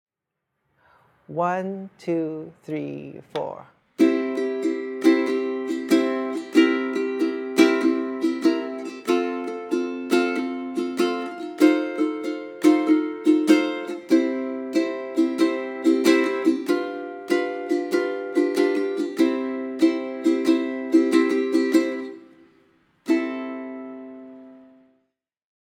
Voicing: Ukulele/CD